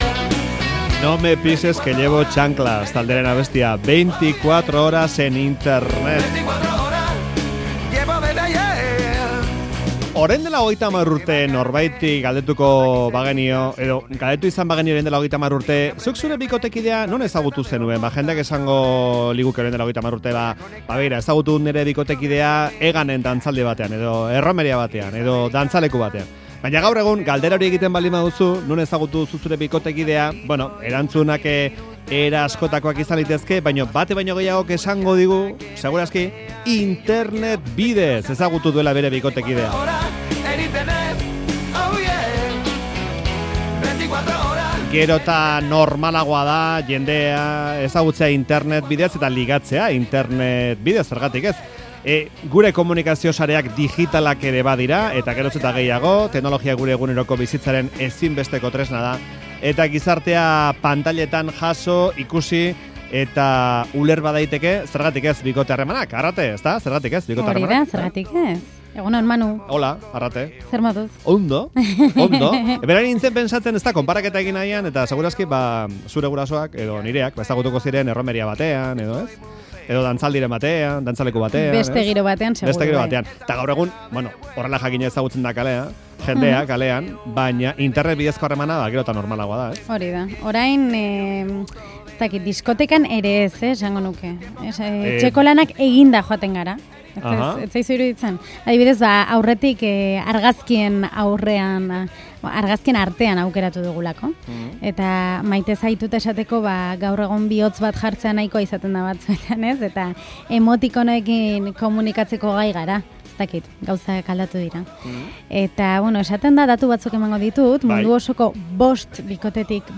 "On line dating-aren" inguruko erreportajea egin dugu. Geroz eta gehiago dira internet eta mugikorreko aplikazioen bidez ligatzen dutenak.